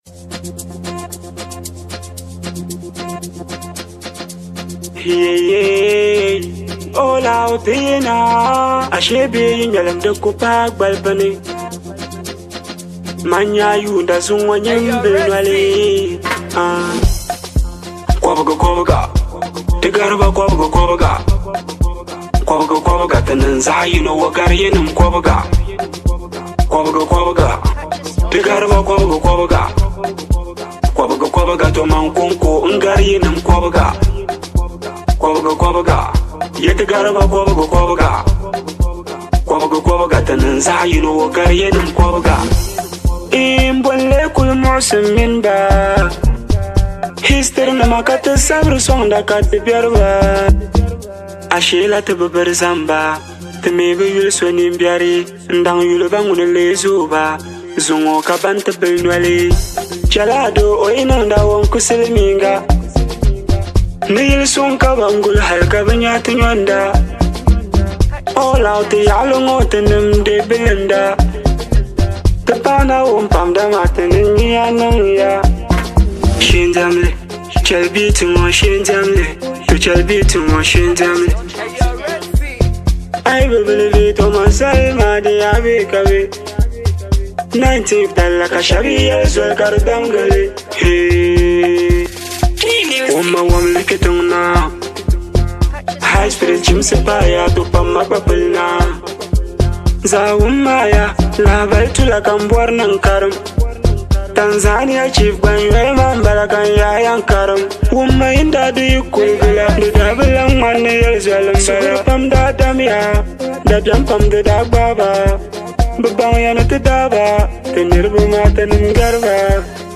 With its motivating vibe and catchy rhythm